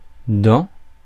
Ääntäminen
Ääntäminen France Tuntematon aksentti: IPA: /dɑ̃/ Haettu sana löytyi näillä lähdekielillä: ranska Käännöksiä ei löytynyt valitulle kohdekielelle.